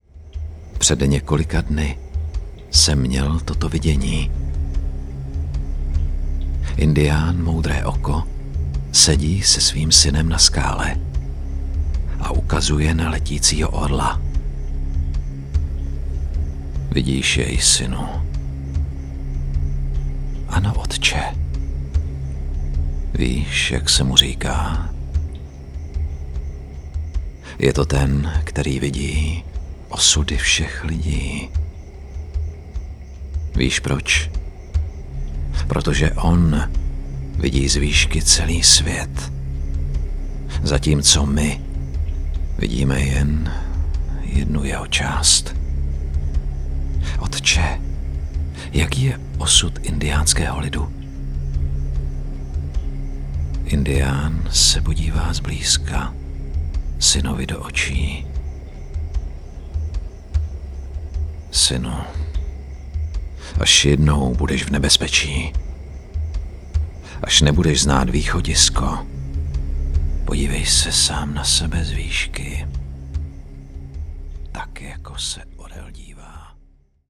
Umím: Voiceover
Vzhledem k sedmileté praxi v divadelní činohře jsem schopen s hlasem pracovat a přizpůsobit jej Vašim přáním a požadavkům.
Poselství moudrého  indiána - ukázka autorské povídky.mp3